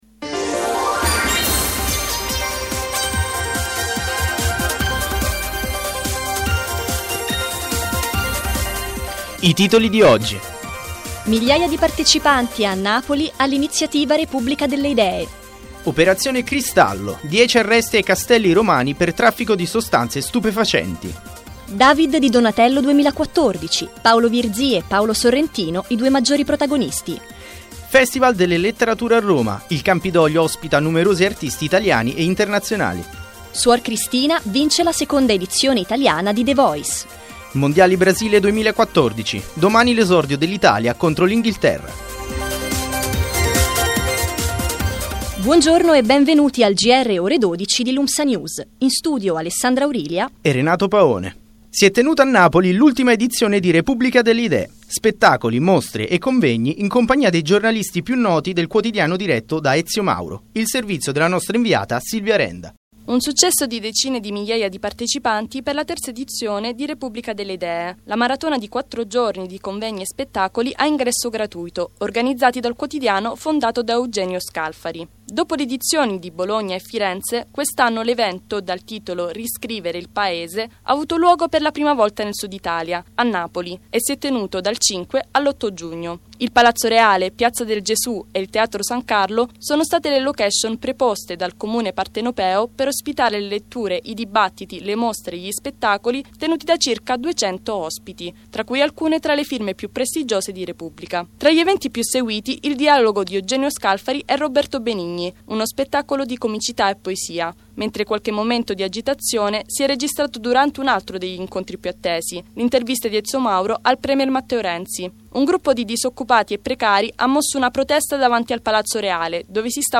Gr ore 12 del 13 giugno 2014